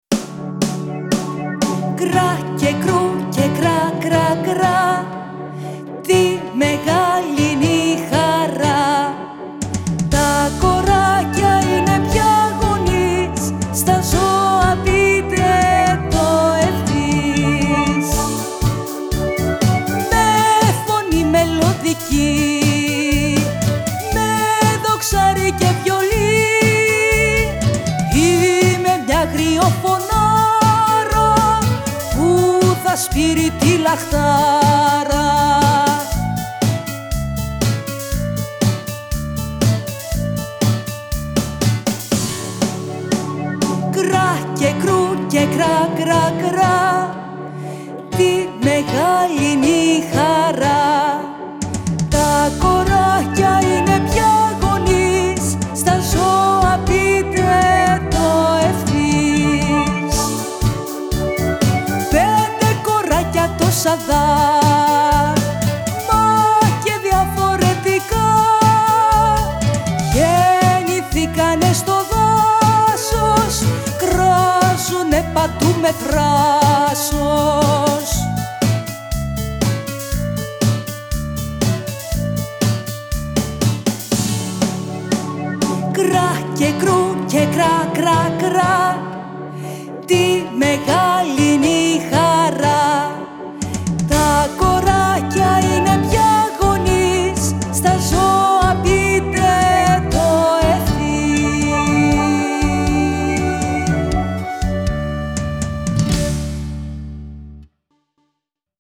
στο studio FREQ